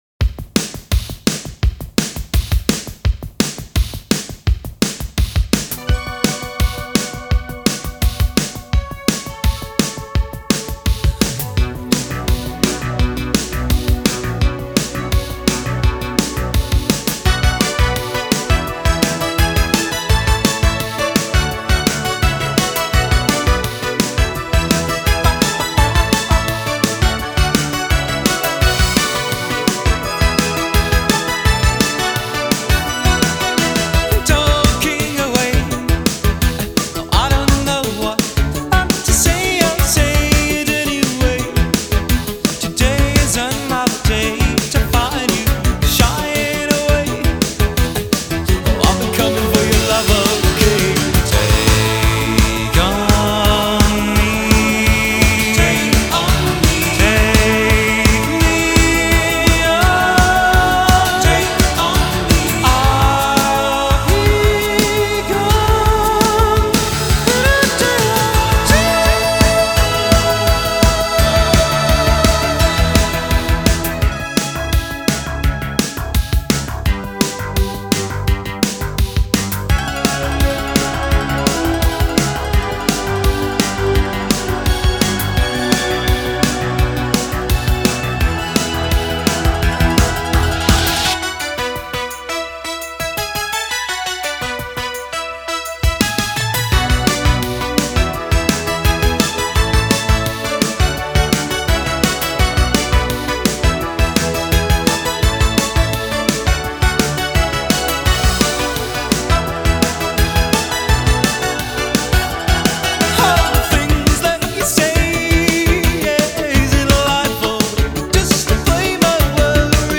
Audio QualityPerfect (High Quality)